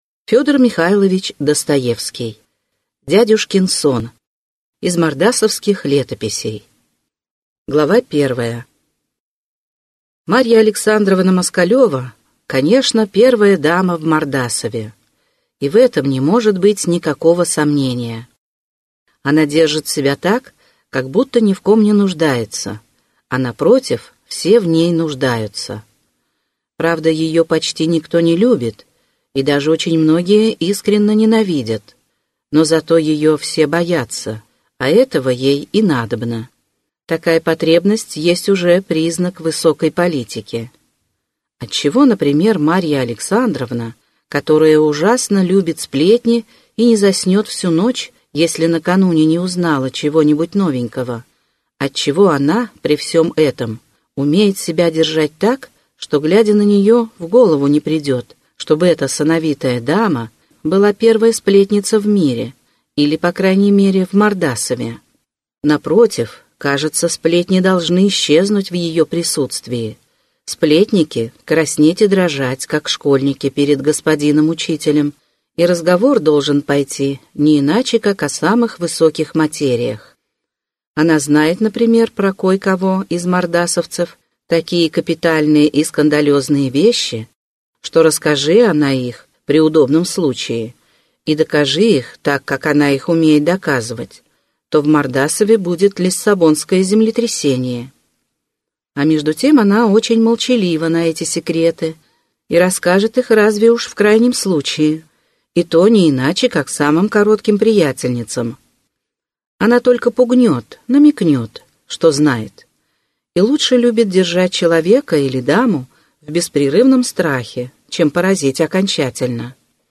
Аудиокнига Дядюшкин сон - купить, скачать и слушать онлайн | КнигоПоиск